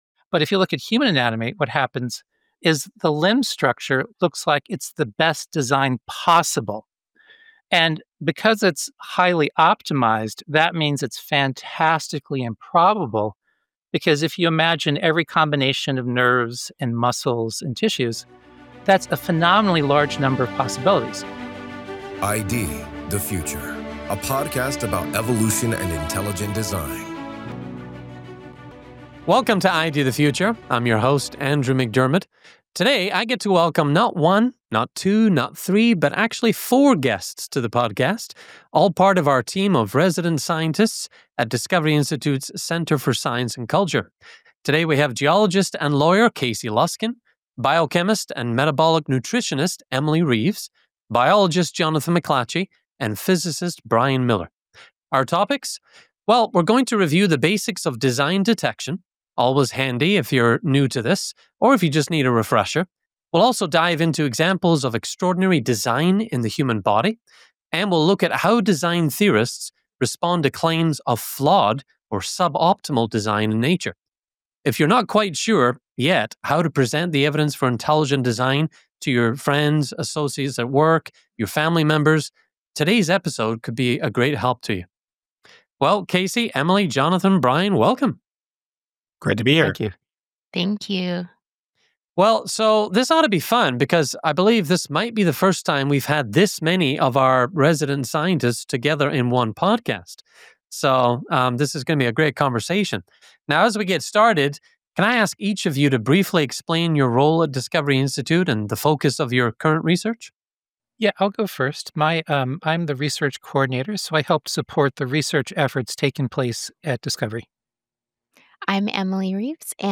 Scientist Roundtable: Examples of Intelligent Design in the Human Body